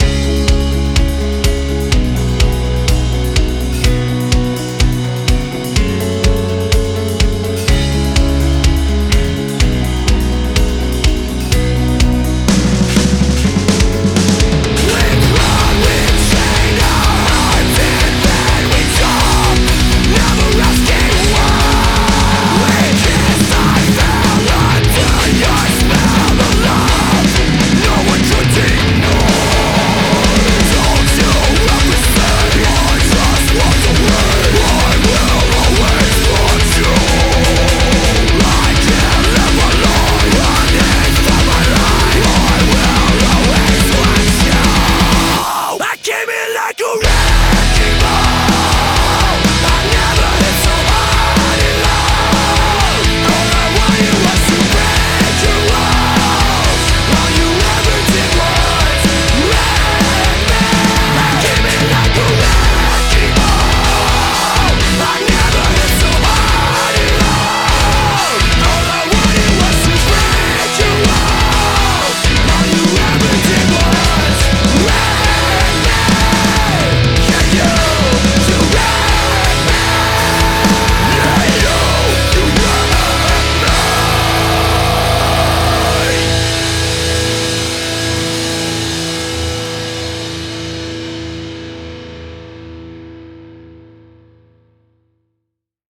BPM128
Audio QualityMusic Cut